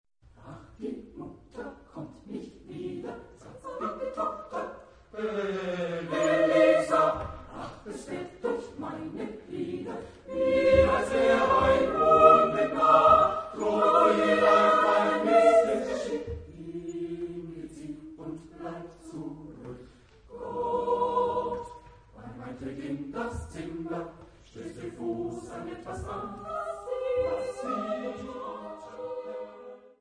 Genre-Style-Form: Madrigal ; ballad ; ; Cycle ; Secular
Type of Choir: SATTB  (5 mixed voices )
Tonality: free tonality